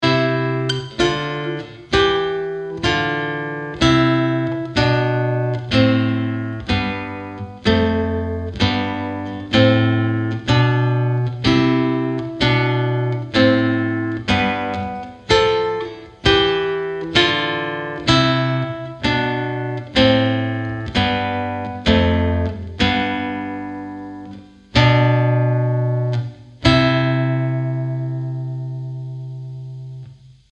Chitarra sola 52